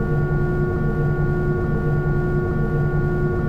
v2500-idle.wav